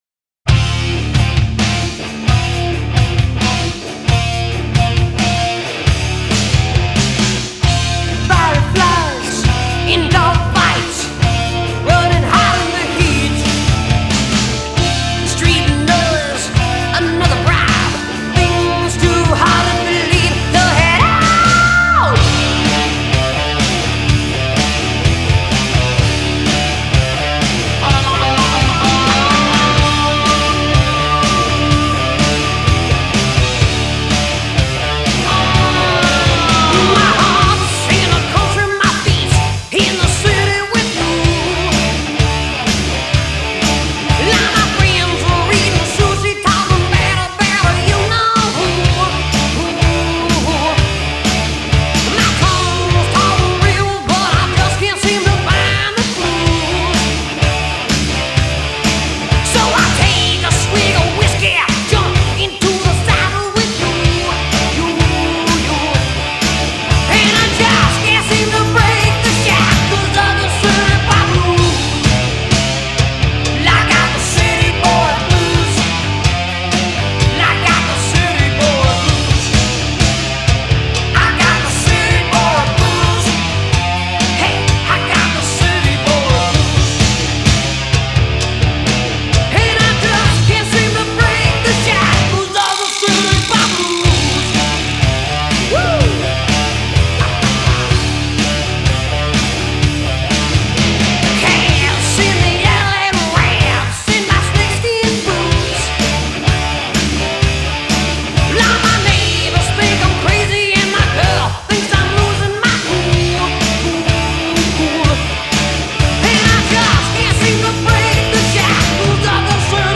Genre : Rock, Metal